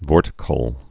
(vôrtĭ-kəl)